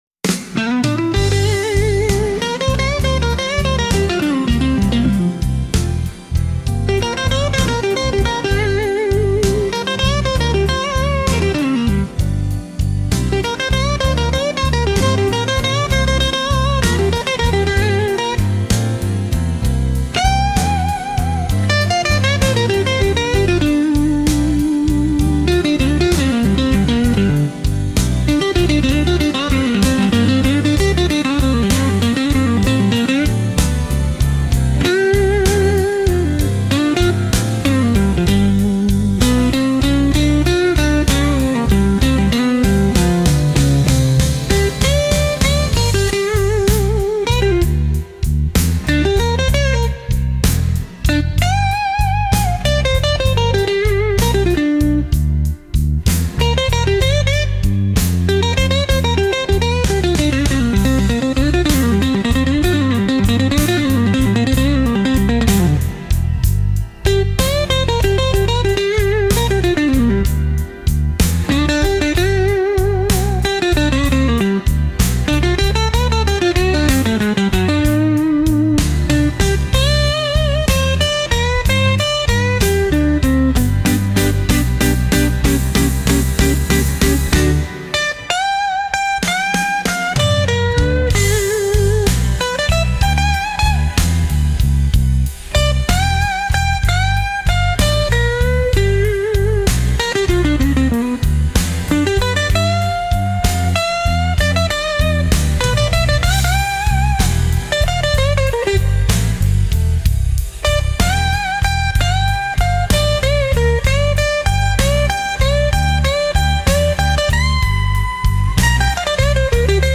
Instrumental - RLMradio Dot XYZ - 4. min.mp3 - Grimnir Radio